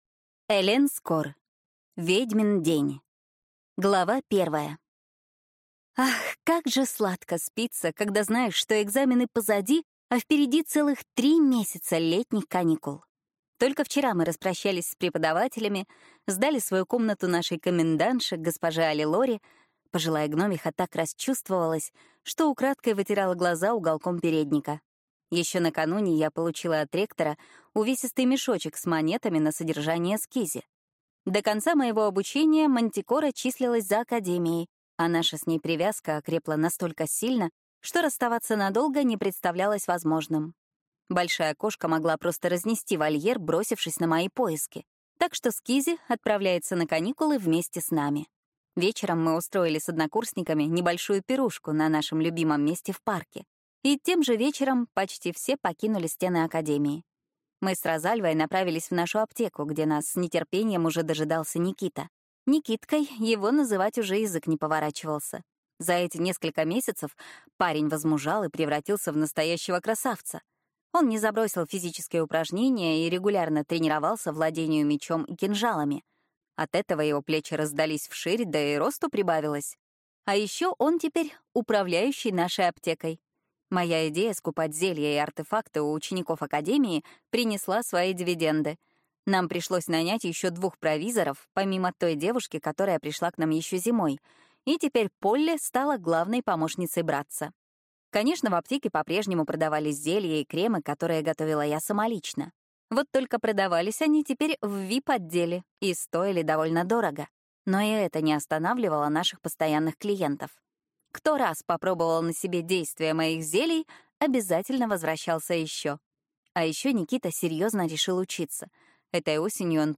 Аудиокнига Ведьмин день | Библиотека аудиокниг
Прослушать и бесплатно скачать фрагмент аудиокниги